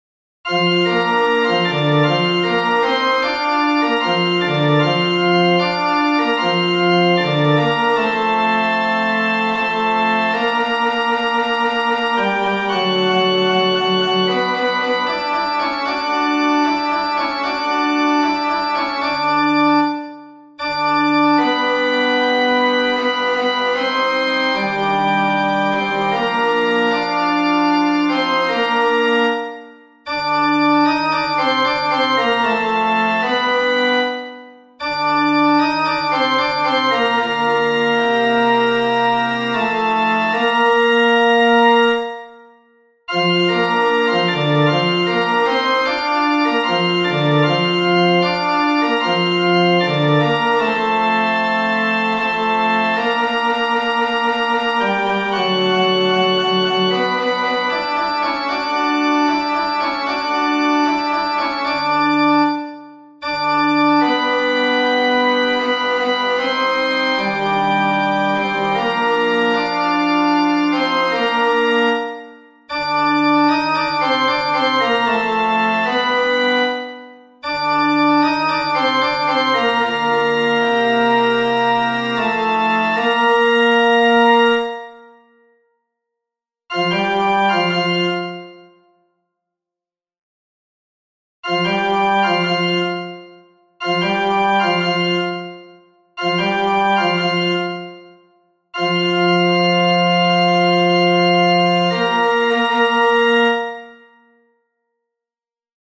FF:HV_15b Collegium male choir
Pivecko-baryton.mp3